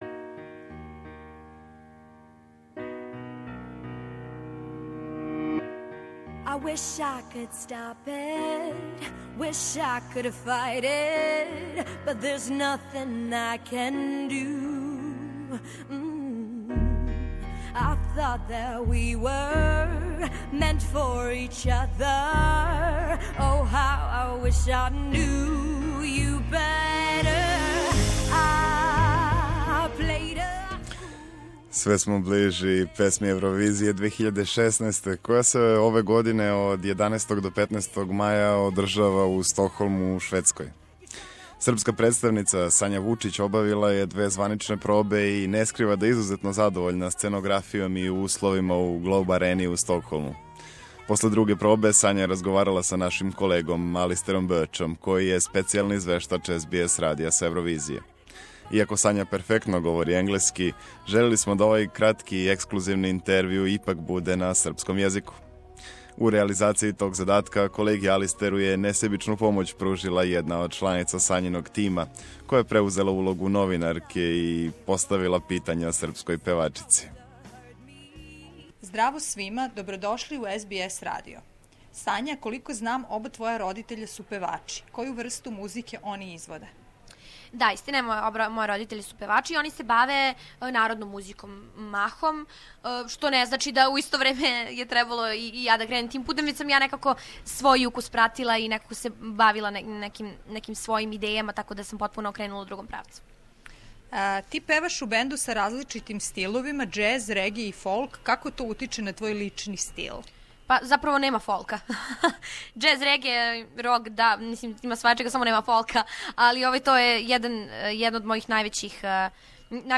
Српска представница на Евровизији Сања Вучић одговарала на питања СБС радија у ексклузивном интервјуу, после друге званичне пробе у Глоуб Арени у Стокхолму. Сања је говорила о различитости музичких жанрова које пева, о свом бенду ЗАА, као и о родитељима који су такође музичари...